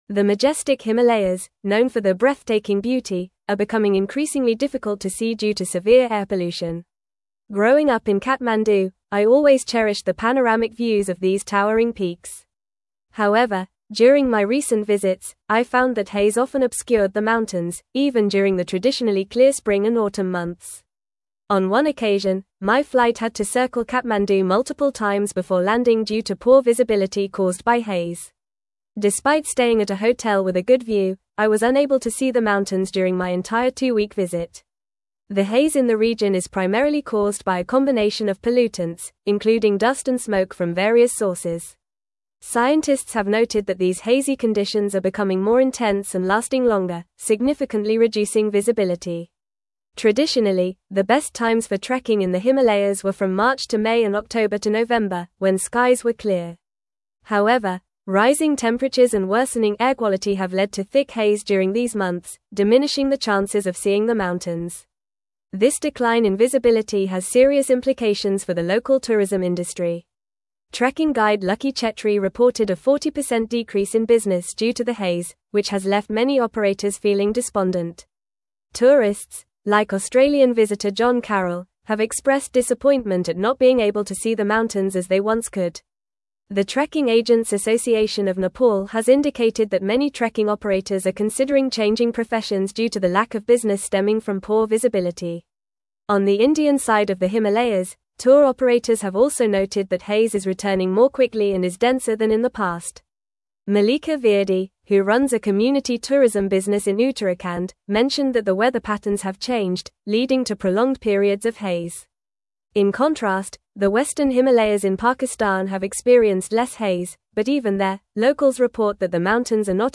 Fast
English-Newsroom-Advanced-FAST-Reading-Himalayan-Views-Obscured-by-Rising-Air-Pollution.mp3